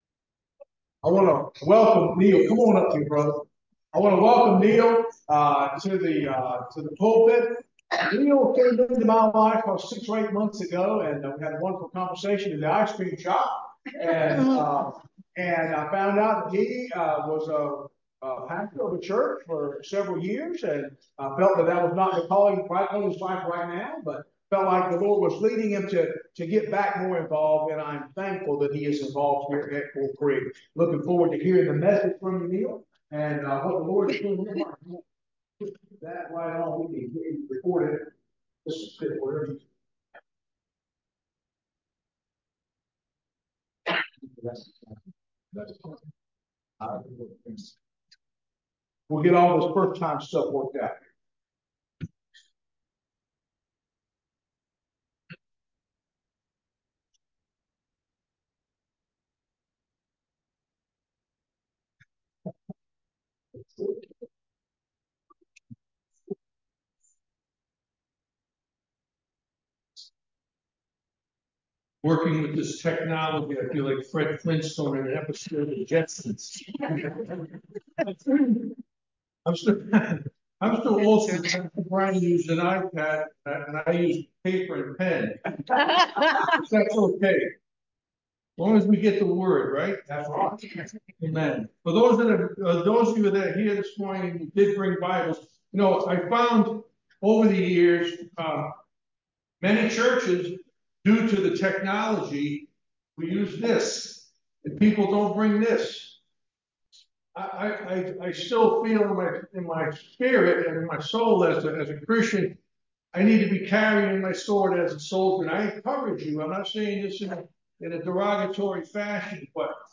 Sermon for 12/31/2023